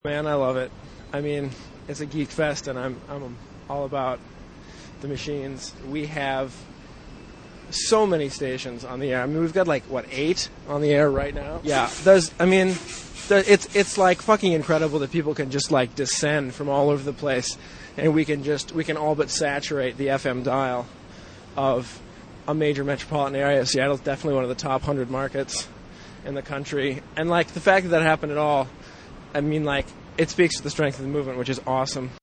We'll begin with cuts from interviews done with a handful of Mosquito Fleet participants; it was hard to catch people flitting back and forth between transmitters, and some people don't want any publicity.